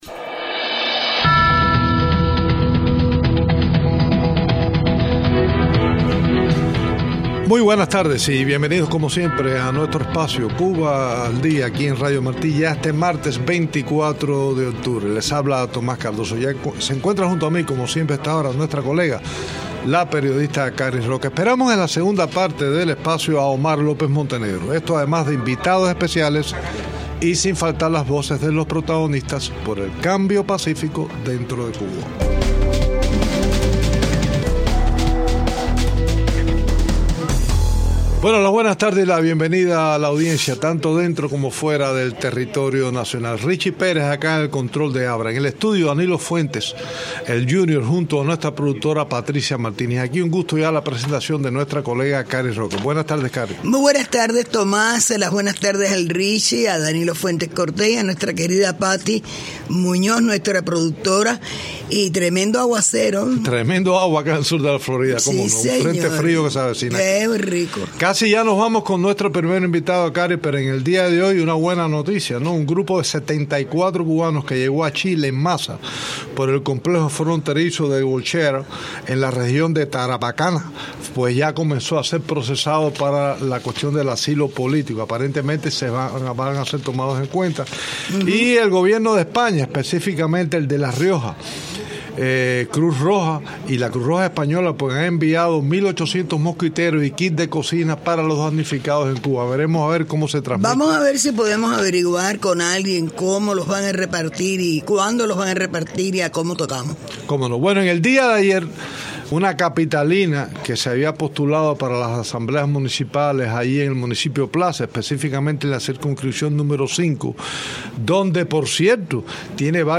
conversaron con candidata que aspiraba ser elegida en comicios del Poder Popular en Cuba.